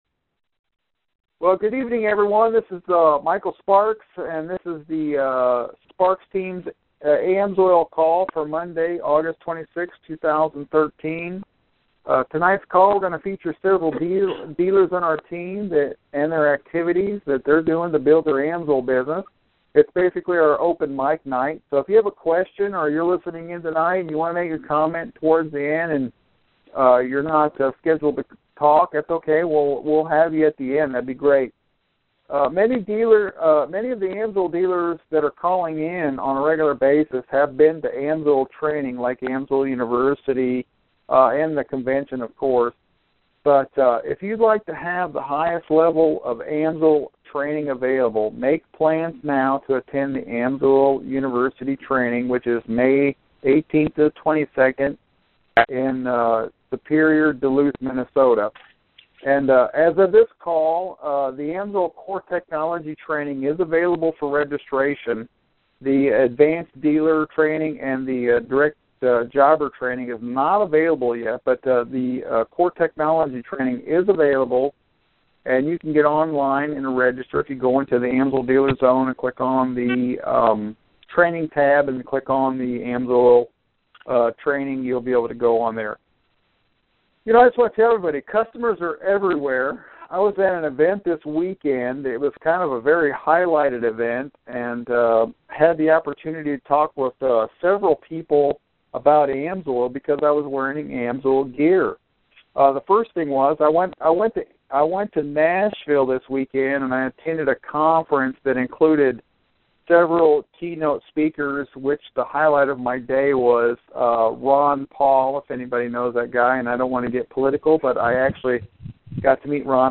Our weekly AMSOIL Dealer training call we have open mic night and talk with several AMSOIL dealers about their successful activities.